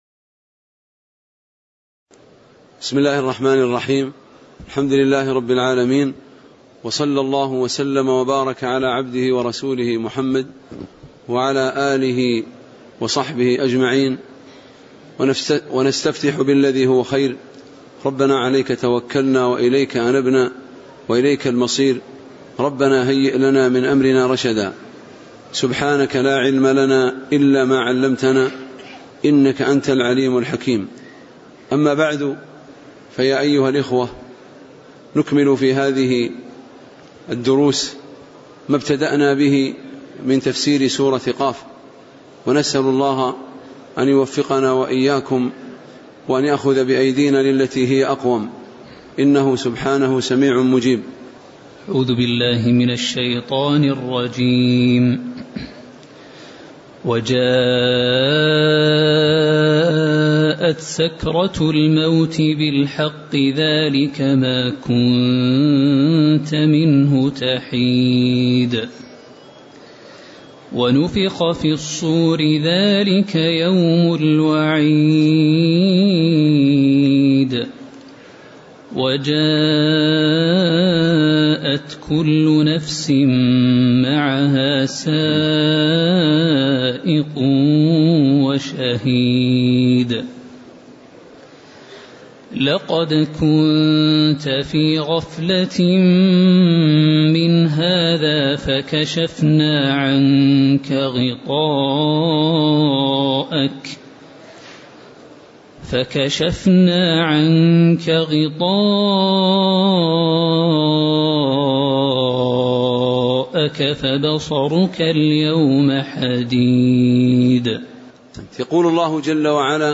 تاريخ النشر ٢٨ ربيع الثاني ١٤٣٩ هـ المكان: المسجد النبوي الشيخ